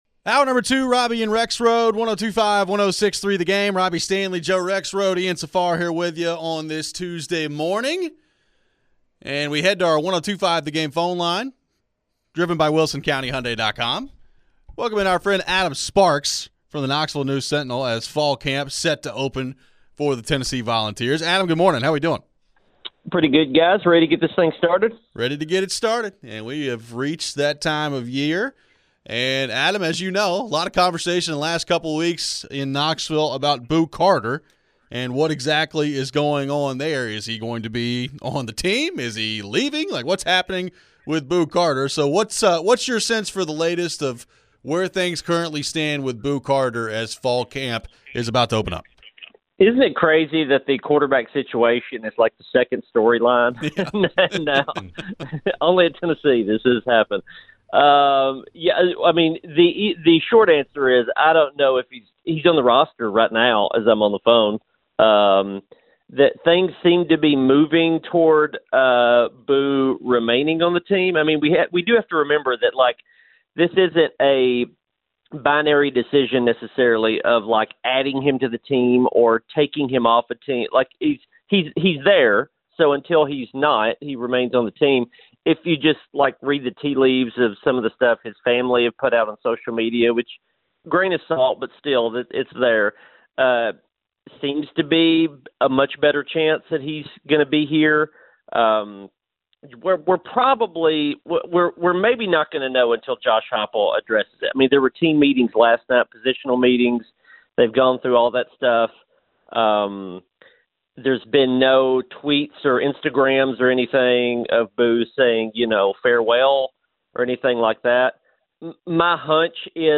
Interview
pre-practice presser